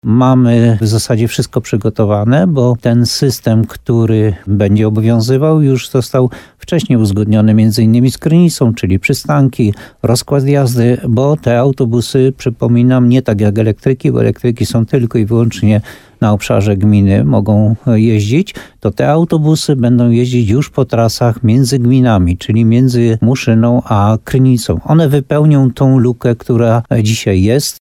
Gmina Muszyna szykuje się na to, żeby od 1 maja uruchomić kolejne połączenia w ramach transportu publicznego. Nowe linie będą obsługiwane przez nowe pojazdy, które już dotarły do uzdrowiska – mówi Jan Golba, burmistrz Muszyny.